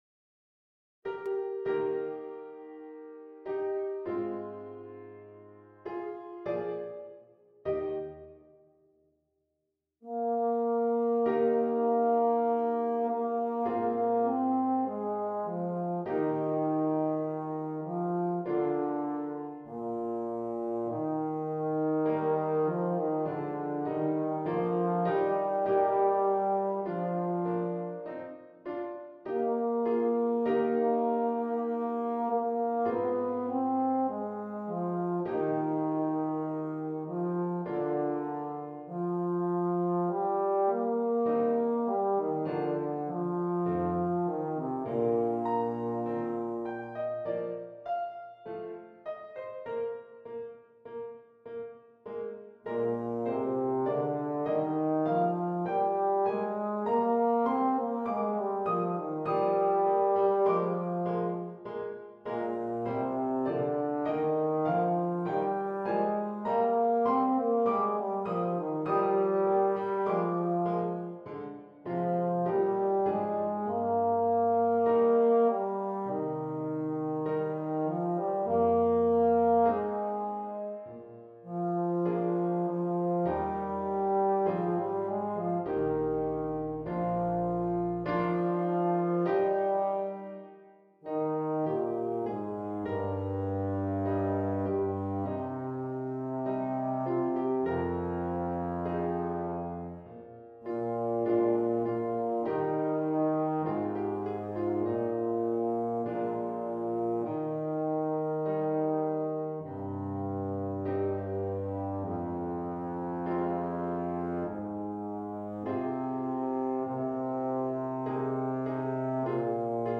mit Klavier
Euphonium (Solo), Euphonium in C – Bass-Schlüssel (Solo)
Klassik
Klavierbegleitung